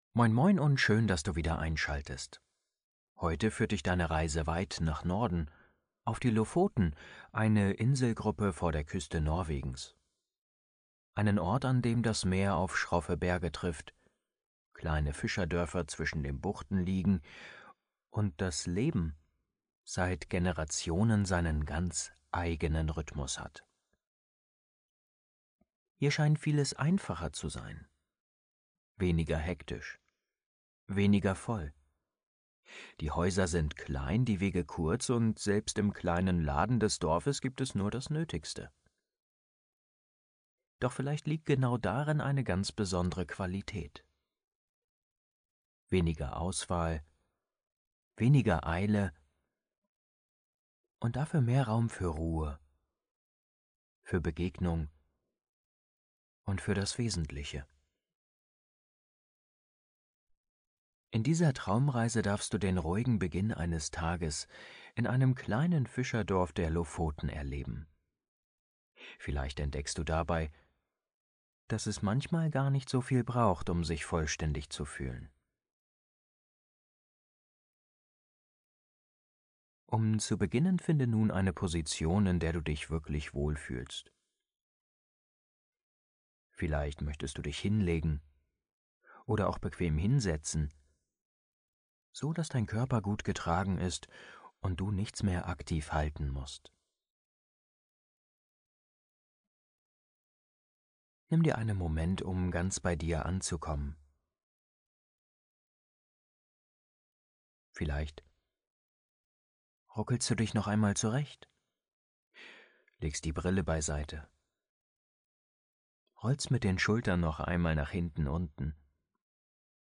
Traumreise zum Einschlafen: Die Lofoten – Ruhe, Meer & nordische Gelassenheit ~ Entspannungshelden – Meditationen zum Einschlafen, Traumreisen & Entspannung Podcast